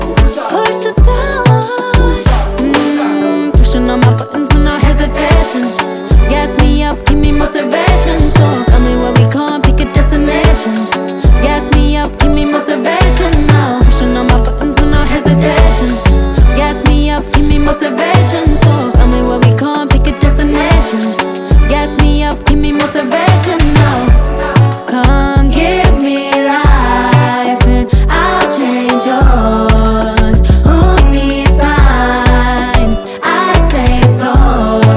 Жанр: Поп / R&b / Соул